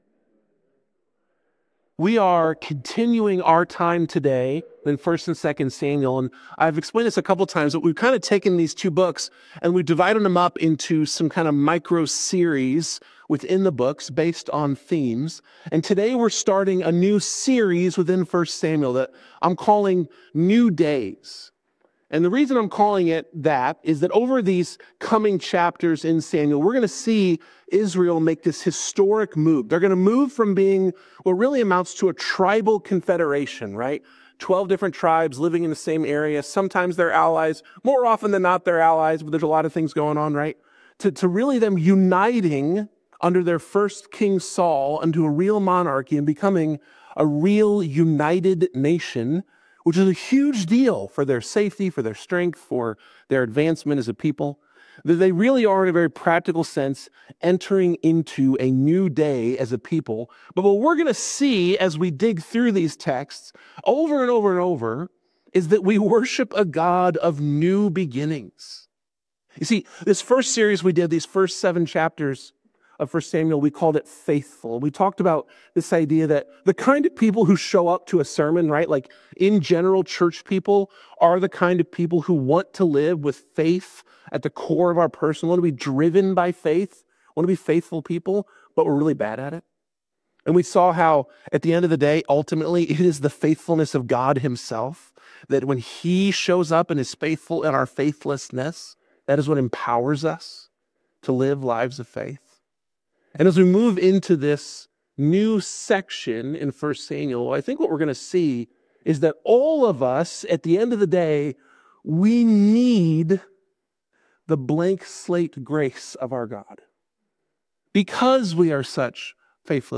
This sermon is part of our ongoing series through 1 and 2 Samuel, exploring how God brings His people from brokenness to blessing and is especially r